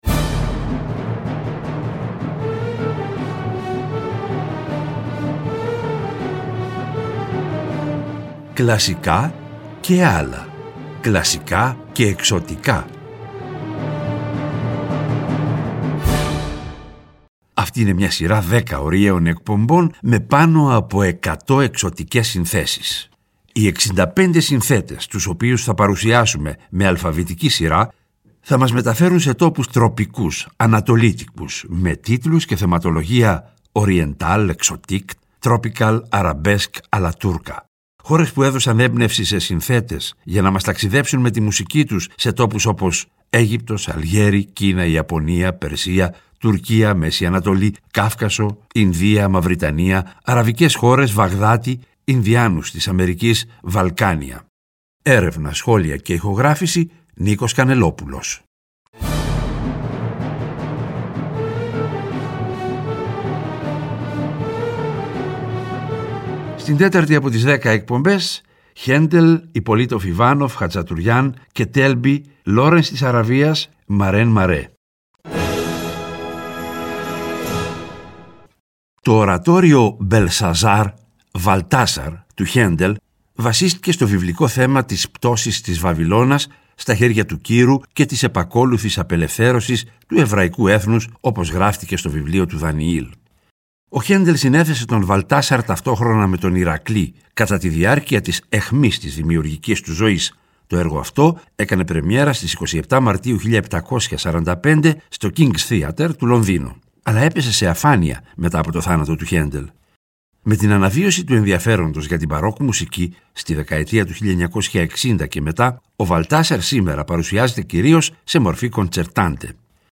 Τον Απρίλιο τα «Κλασικά και ..Άλλα» παίρνουν χρώμα Ανατολής και γίνονται «Κλασικά και …Εξωτικά», σε μια σειρά 10 ωριαίων εκπομπών, με πάνω από 100 εξωτικές συνθέσεις.